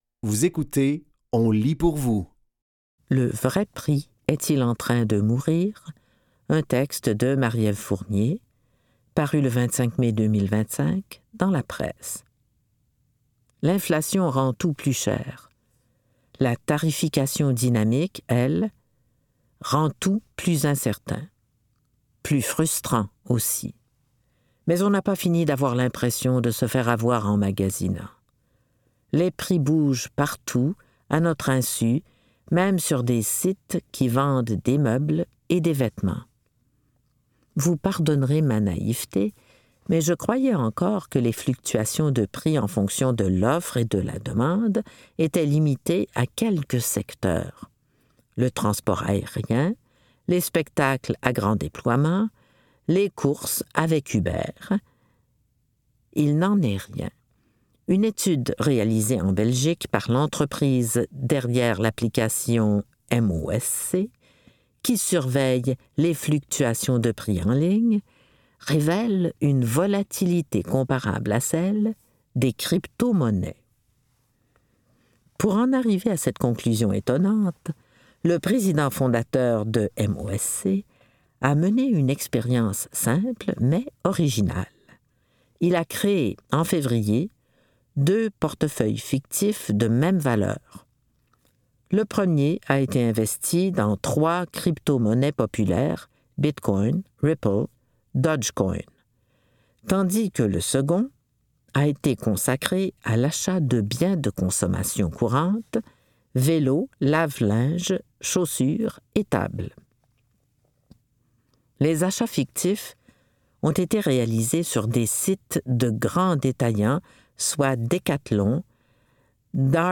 Dans cet épisode de On lit pour vous, nous vous offrons une sélection de textes tirés du média suivant : La Presse, Le Devoir et La Gazette des Femmes.